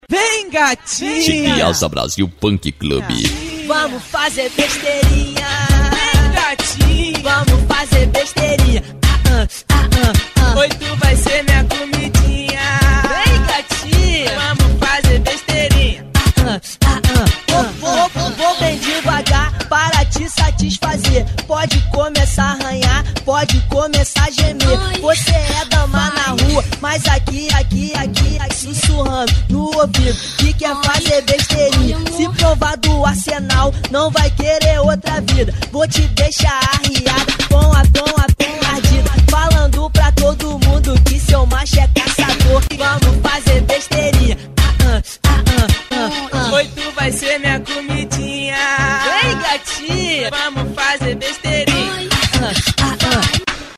Funk
Funk Nejo